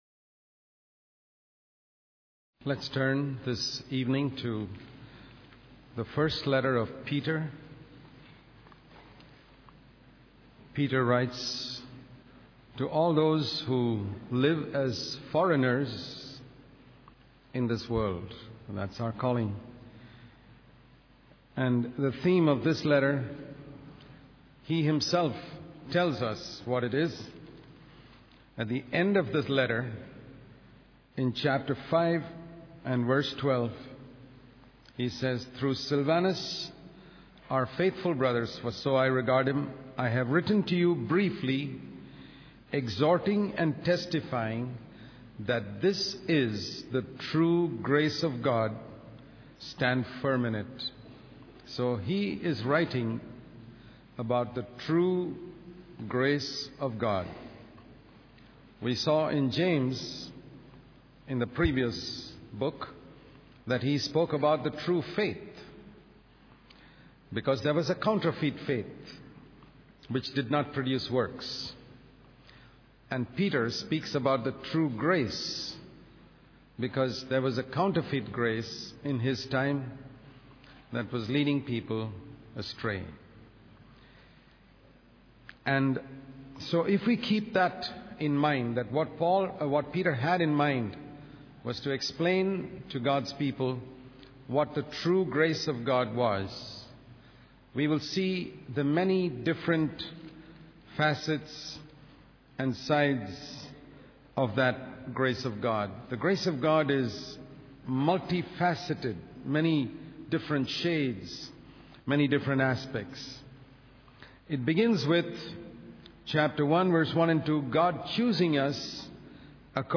In this sermon, the preacher emphasizes the role of the Holy Spirit in the lives of believers.